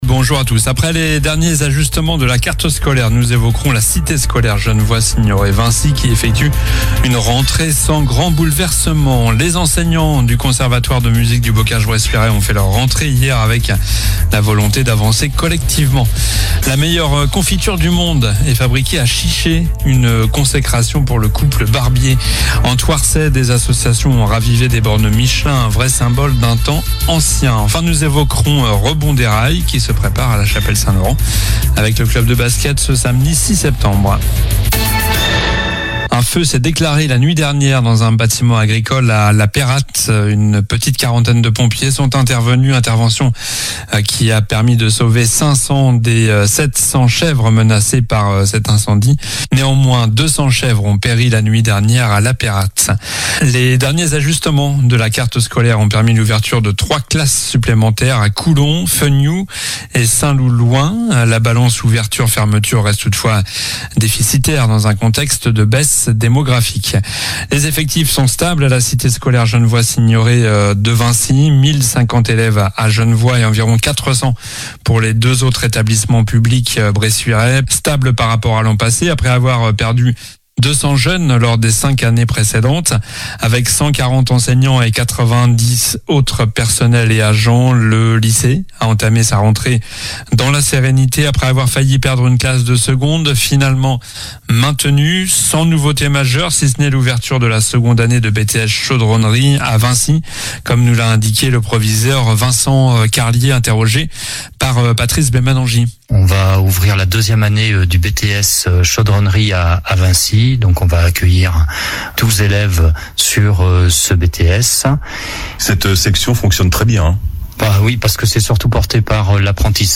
Journal du mercredi 03 septembre (midi)